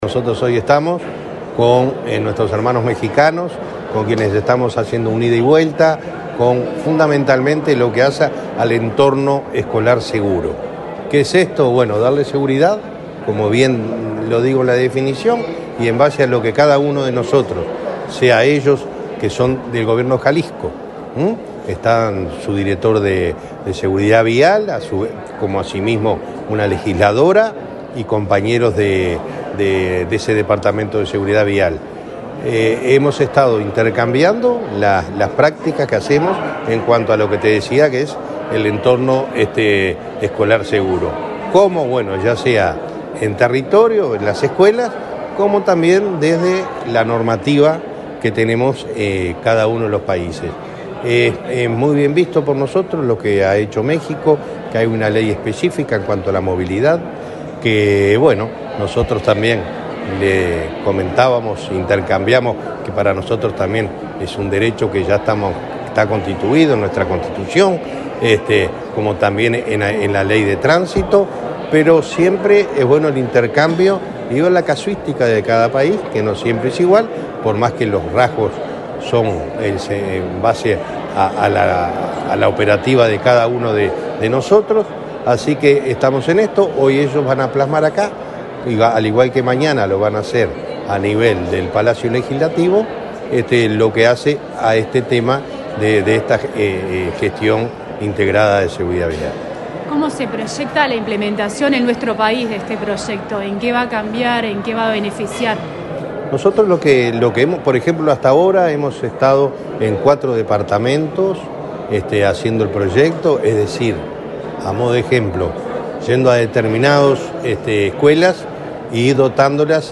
Entrevista al presidente de Unasev, Alejandro Draper
En el marco del proyecto “Bases para una gestión integrada de la Seguridad Vial”, apoyado por el Fondo Conjunto de Cooperación Uruguay – México, una delegación del norte visita nuestro país. El presidente de la Unidad Nacional de Seguridad Vial (Unasev), Alejandro Draper, dialogó con Comunicación Presidencial en la Torre Ejecutiva, acerca del alcance de los encuentros técnicos que se realizaron en esta jornada.